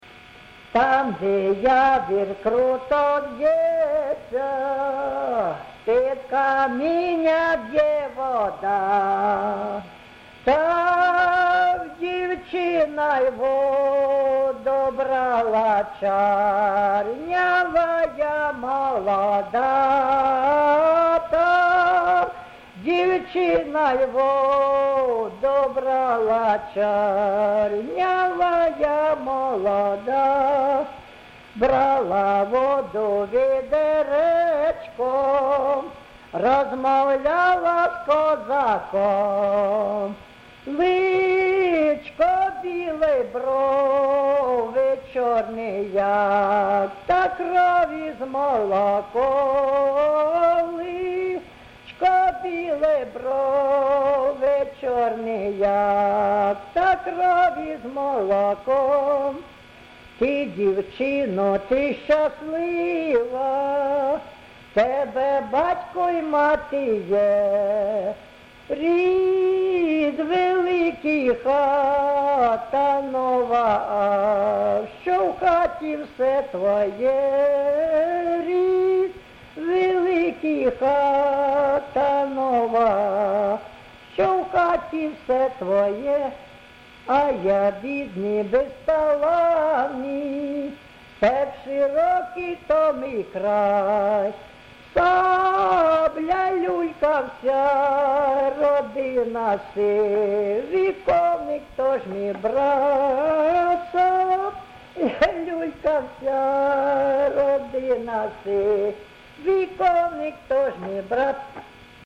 ЖанрПісні літературного походження
Місце записум. Антрацит, Ровеньківський район, Луганська обл., Україна, Слобожанщина